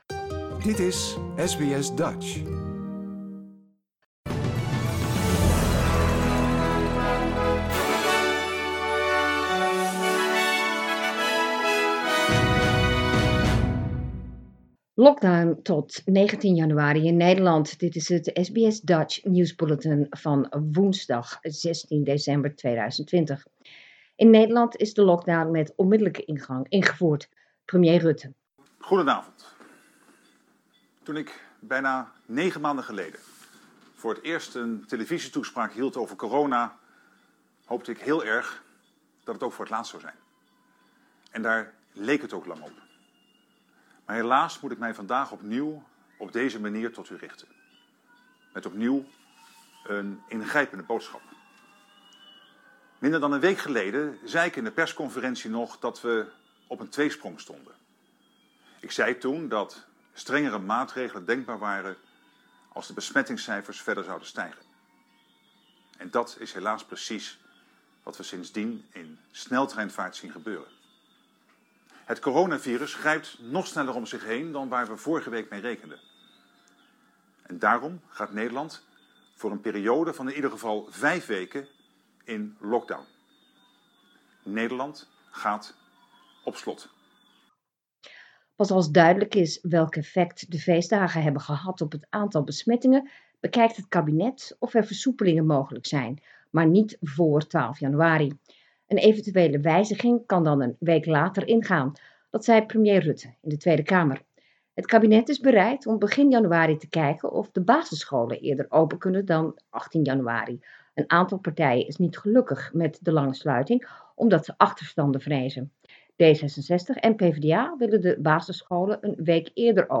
Nederlands/Australisch SBS Dutch nieuwsbulletin woensdag 16 december 2020